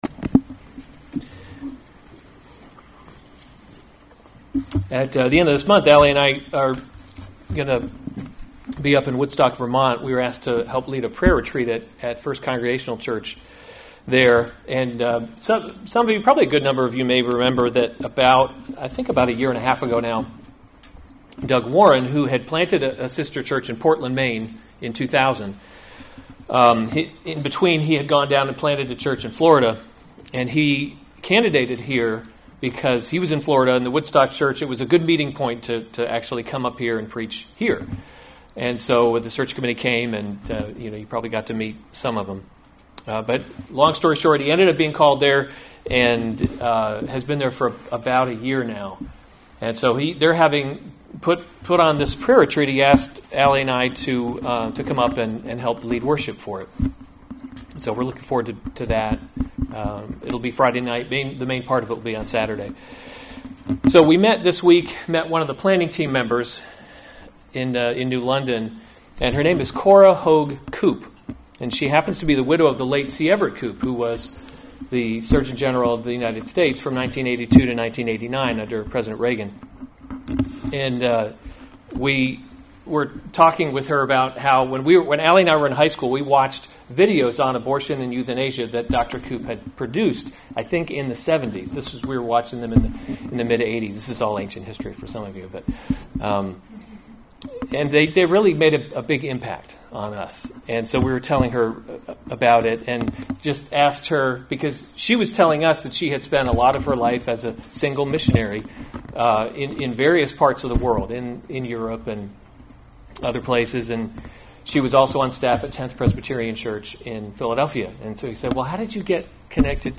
March 7, 2020 2 Peter – Covenant Living series Weekly Sunday Service Save/Download this sermon 2 Peter 1:1-4 Other sermons from 2 Peter Greeting 1:1 Simeon [1] Peter, a servant [2] and apostle […]